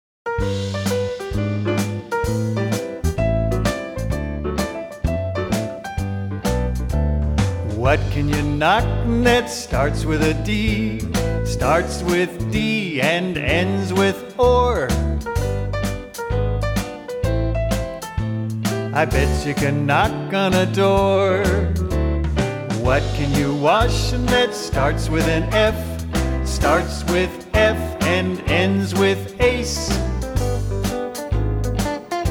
A song for teaching Initial Consonants and Word Families!